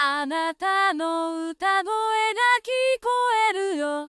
OmniVocal は、Cubase 15 から新しく搭載された 歌声合成エンジン です。